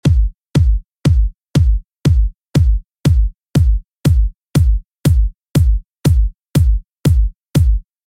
Here is how the drum sounds without compression: